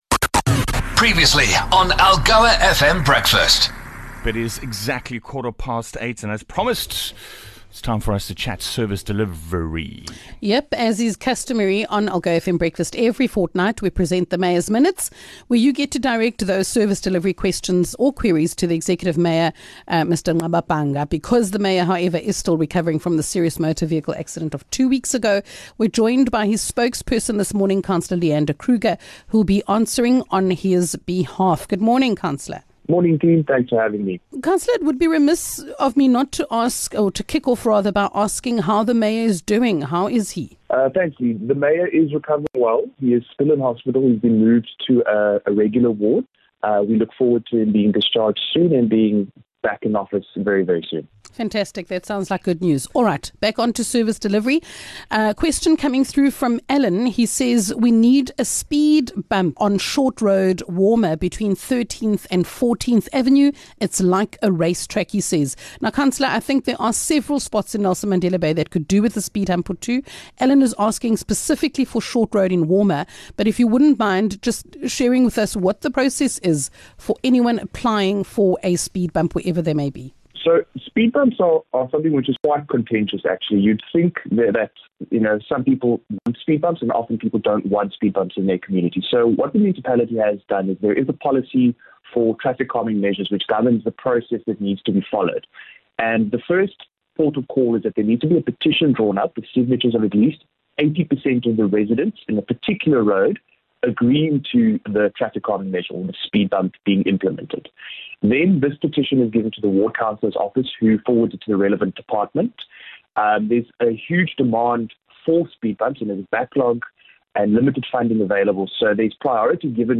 In the absence of the Mayor, who is recovering in hospital after being involved in a serious vehicle accident, Mayoral Spokesperson for the NMB Municipality, Councillor Leander Kruger, stepped into his shoes to answer your questions around pressing service delivery issues.